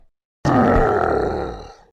Stick War Legacy Giant Death Sound Effect Download: Instant Soundboard Button